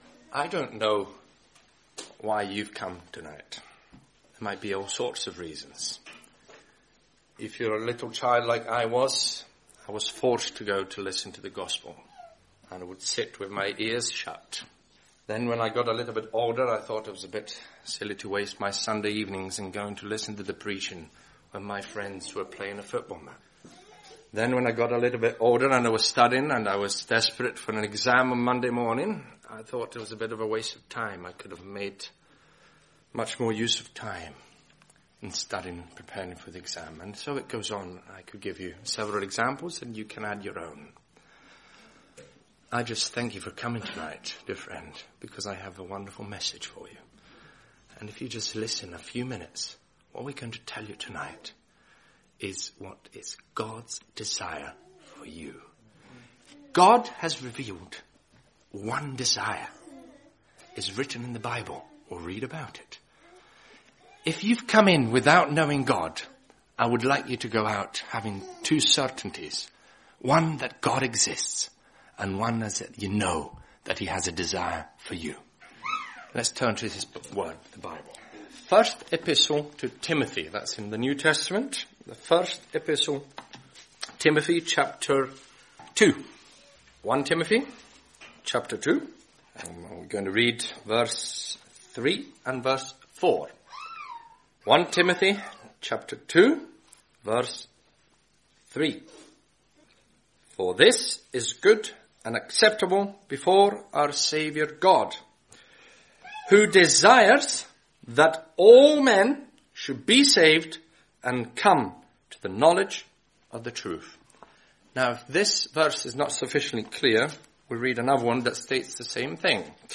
Over 2000 years ago Jesus came into this world to save sinners and provide salvation. Listen to this Gospel preaching to find out more about God's plan of salvation and how you can be SAVED by completed work of Jesus Christ.